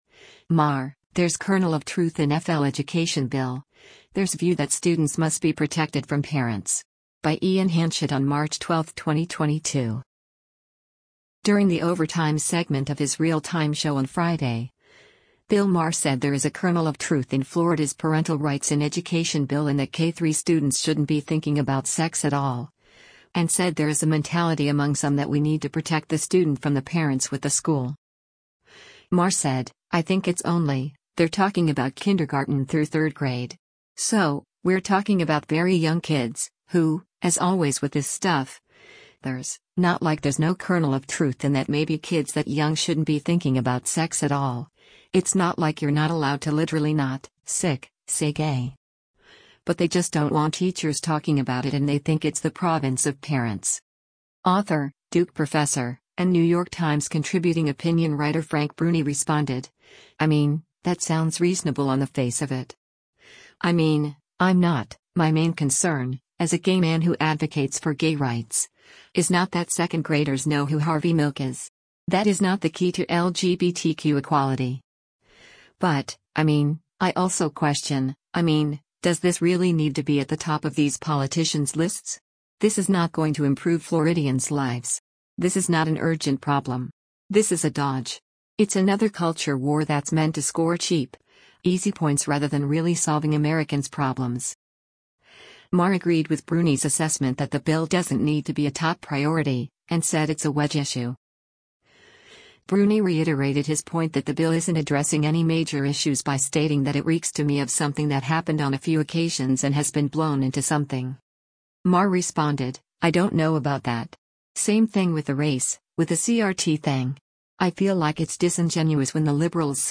During the “Overtime” segment of his “Real Time” show on Friday, Bill Maher said there is a “kernel of truth” in Florida’s Parental Rights in Education bill in that K-3 students “shouldn’t be thinking about sex at all.”
Bruni then sarcastically remarked, “Yeah, that’s a political winner.”